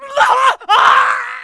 death2a.wav